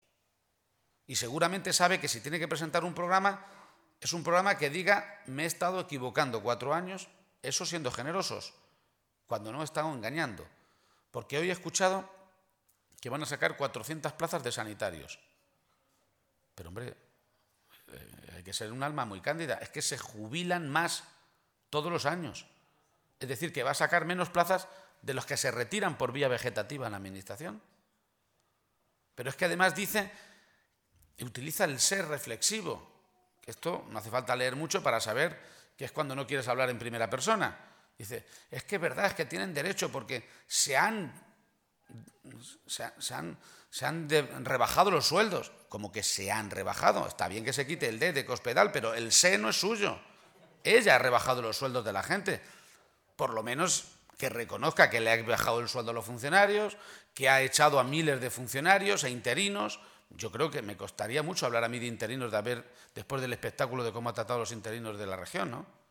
García-Page realizó este anuncio en una comida con militantes y simpatizantes en la localidad toledana de Torrijos, después de visitar esta mañana la zona afectada y mantener un encuentro con la alcaldesa de El Provencio.